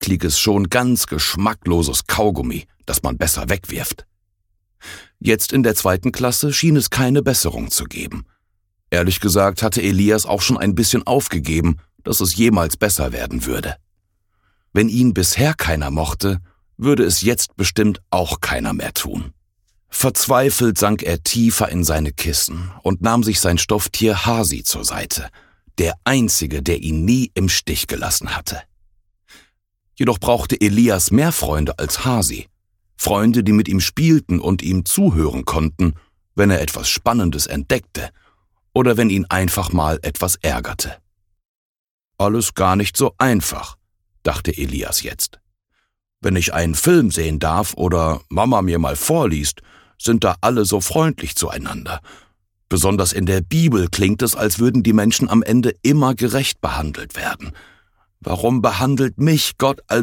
Über Hoffnung, Traurigsein und Trost - Hörbuch